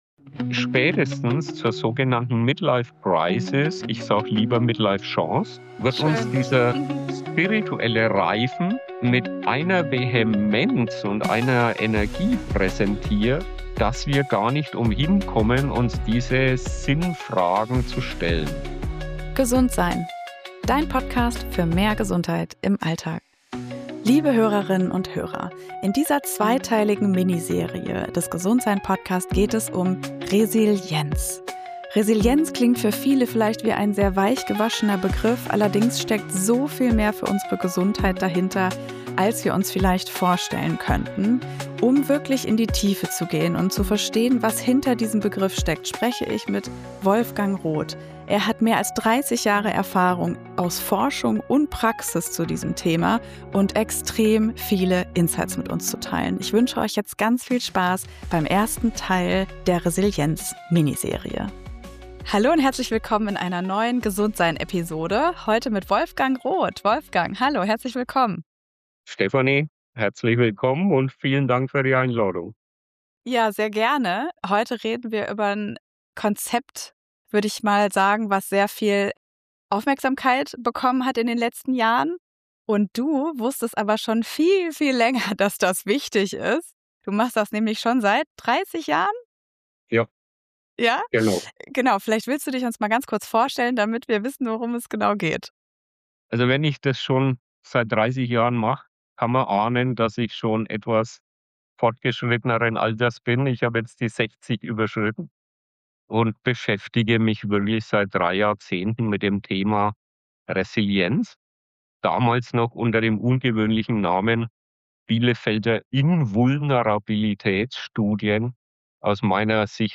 Ein tiefgründiges Gespräch über die „Midlife Chance“, den Mut zur Selbstfreundschaft und die heilende Wirkung fließender Emotionen.